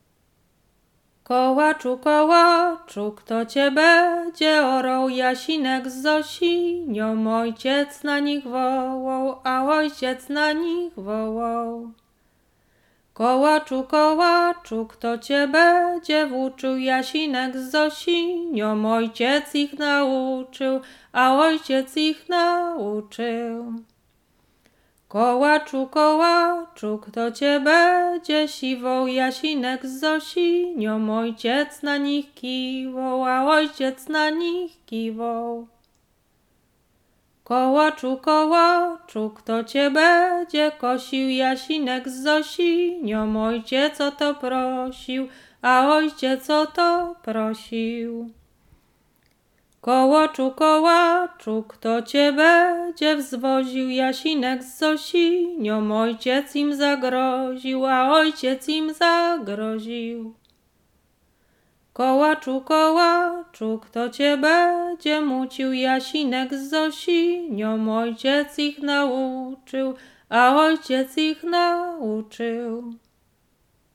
Łęczyckie
Weselna
wesele weselne